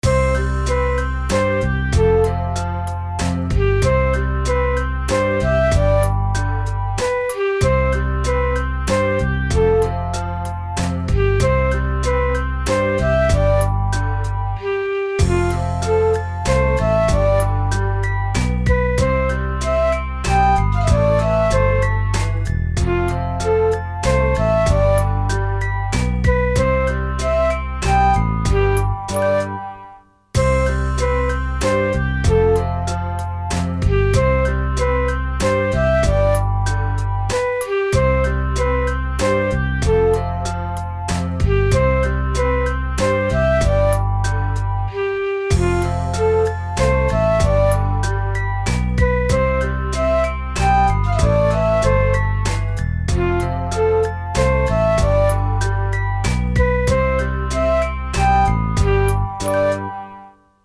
優しい・温かい
優しく和やかな感じ。BPMは遅め。
Aはドラム有り版。